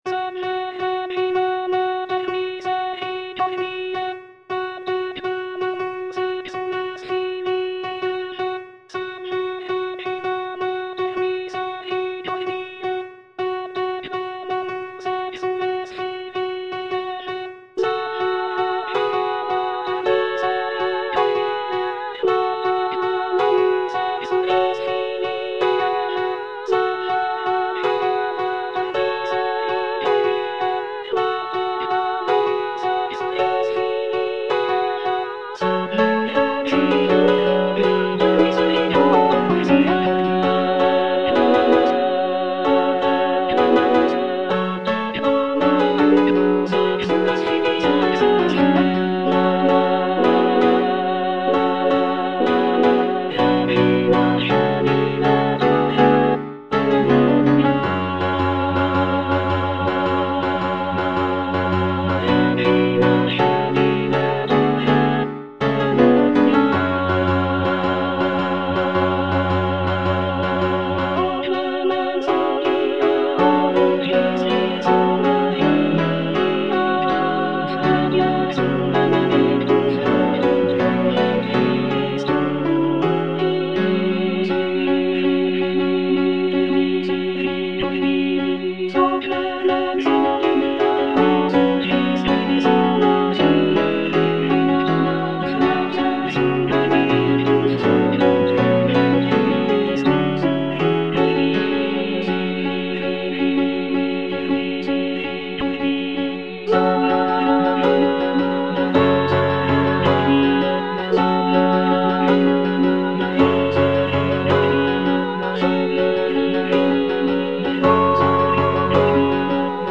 (All voices) Ads stop